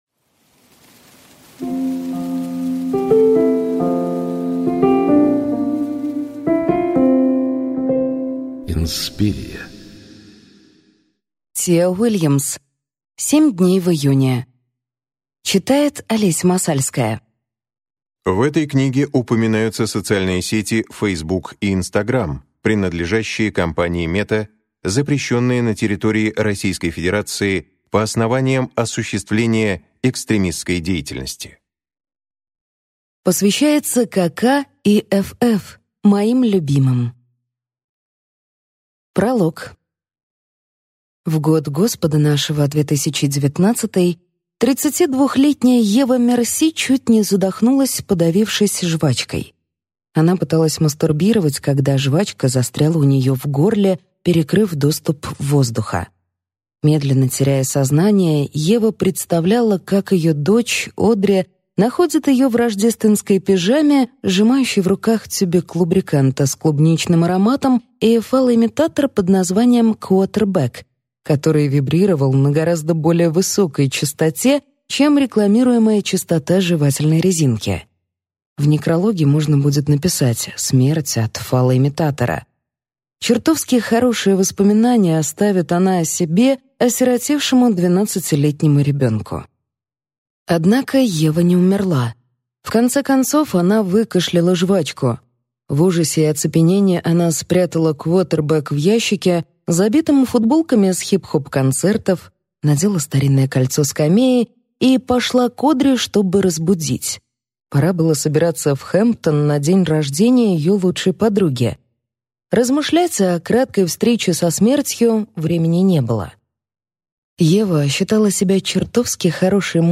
Аудиокнига Семь дней в июне | Библиотека аудиокниг